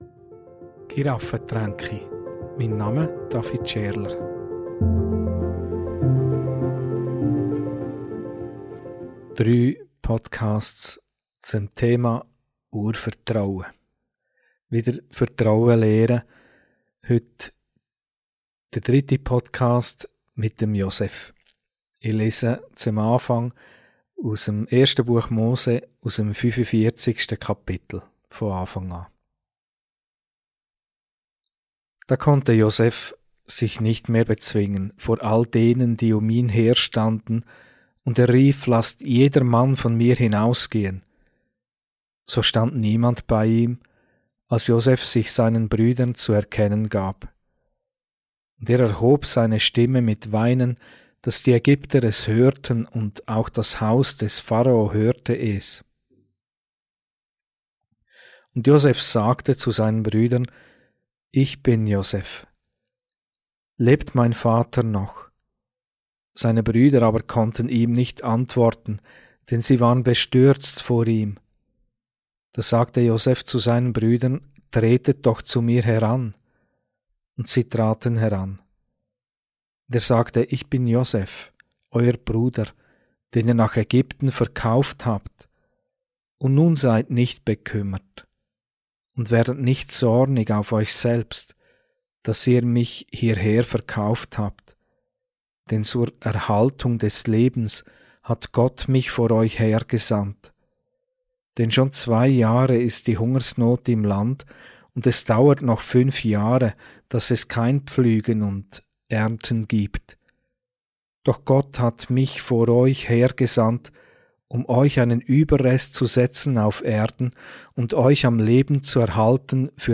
Predigt - Mit Josef im Vertrauen wachsen ~ Giraffentränke Podcast